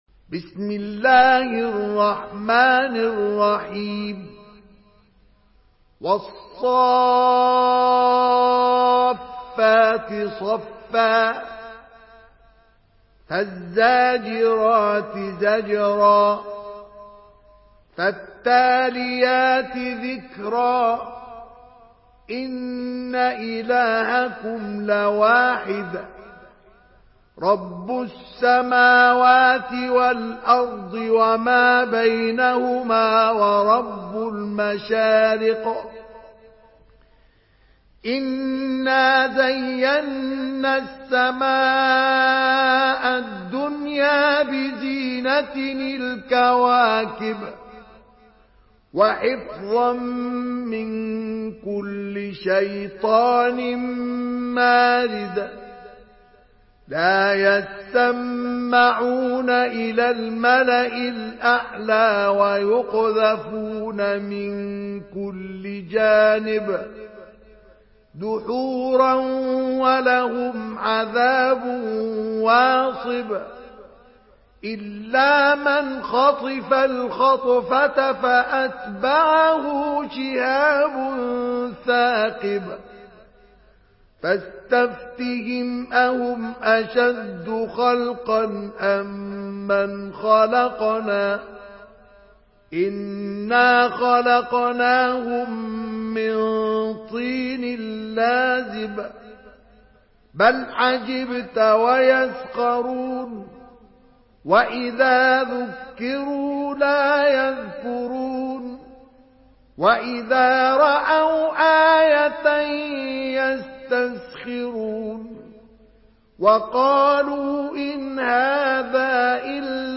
Surah আস-সাফ্‌ফাত MP3 by Mustafa Ismail in Hafs An Asim narration.
Murattal